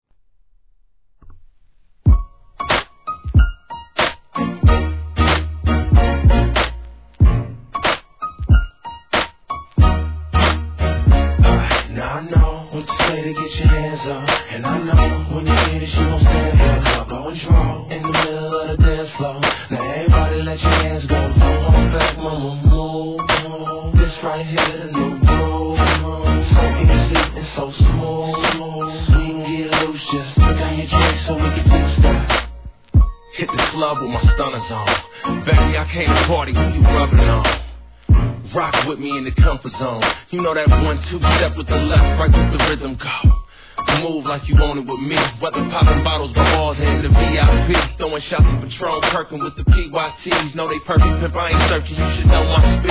G-RAP/WEST COAST/SOUTH
モロにDRE直系のサウンドにG-UNIT周辺の雰囲気をかもし出します!!